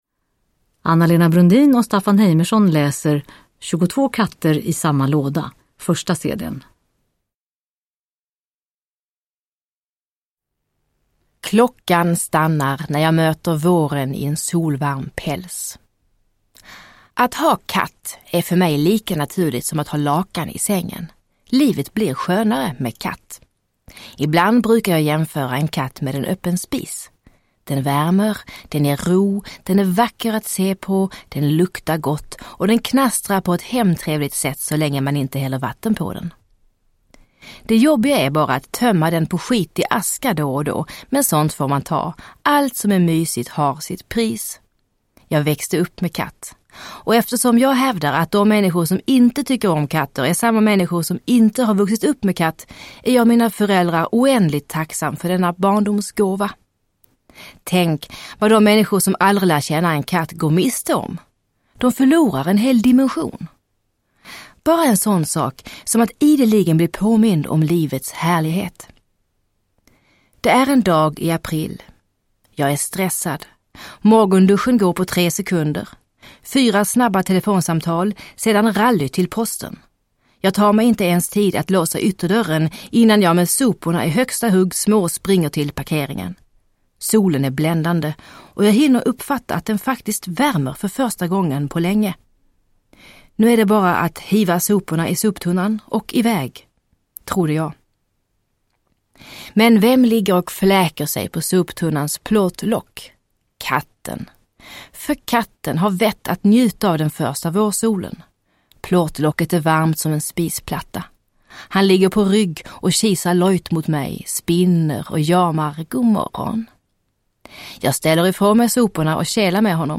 22 Katter i samma låda – Ljudbok – Laddas ner
Lyssna på komikern och författaren Anna-Lena Brundin och världsreportern och resenären Staffan Heimerson när de med värme och mycket kärlek berättar om Bosse, Mjau och andra härliga katter.
Uppläsare: Staffan Heimerson, Anna-Lena Brundin